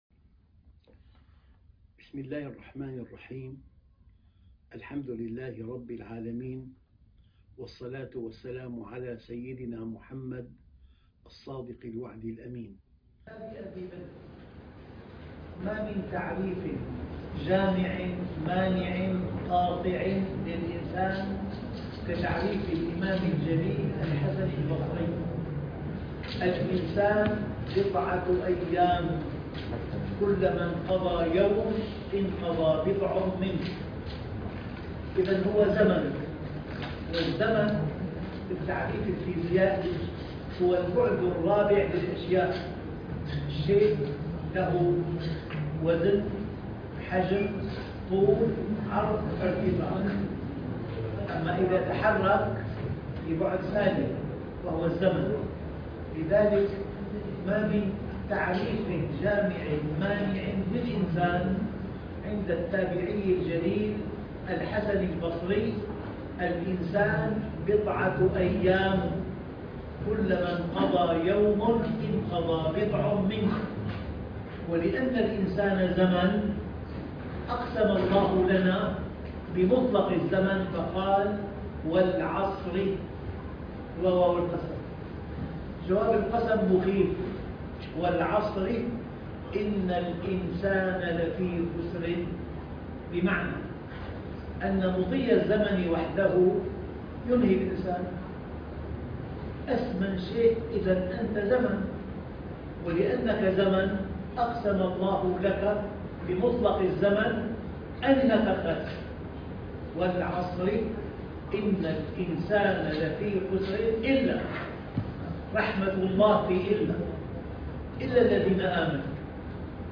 محاضرات خارجية – العراق – محاضرات مختلفة أركان النجاة الأربعة - الشيخ محمد راتب النابلسي
عنوان المادة محاضرات خارجية – العراق – محاضرات مختلفة أركان النجاة الأربعة